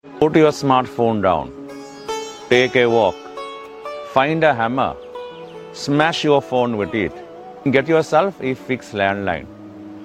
Smash your mobile phone